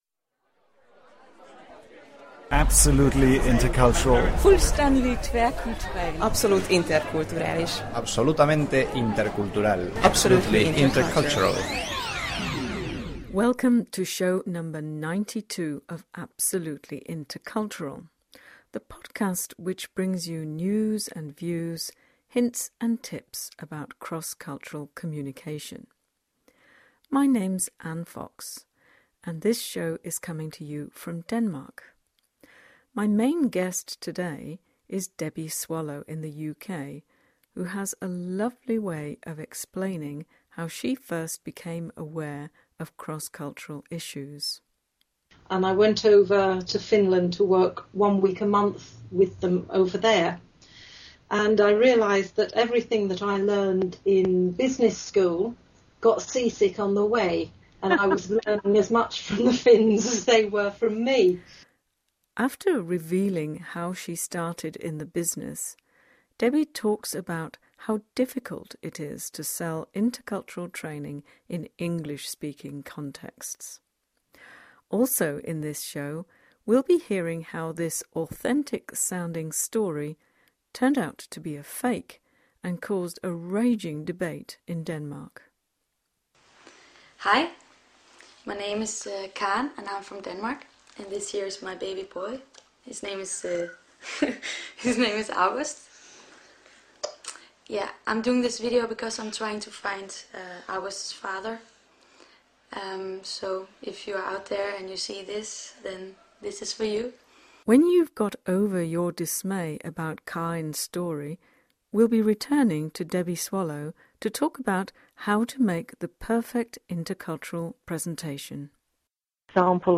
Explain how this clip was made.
What you’re about to hear is the audio track from a You Tube video.